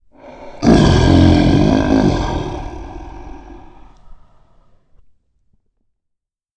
Lion Roar
Category 🐾 Animals
angry animal beast cat creature exotic fake feline sound effect free sound royalty free Animals